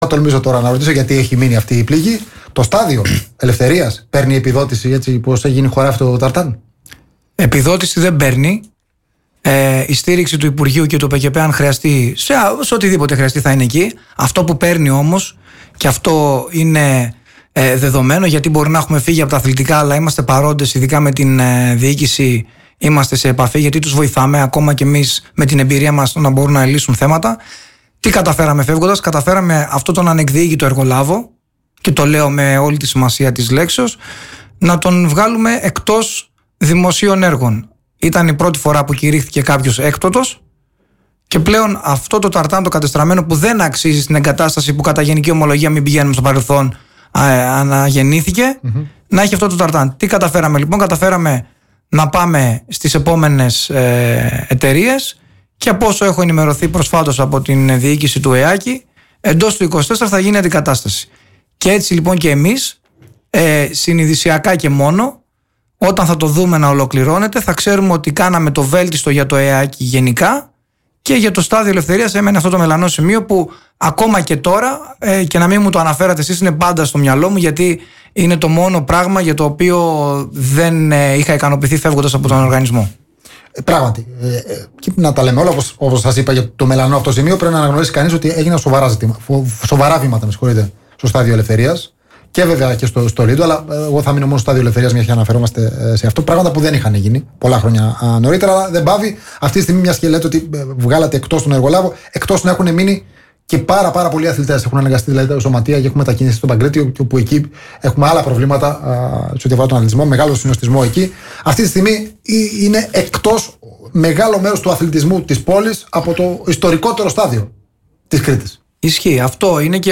Καλεσμένος στο studio της εκπομπής ο νέος Αντιπρόεδρος του ΟΠΕΚΕΠΕ Λευτέρης Ζερβός.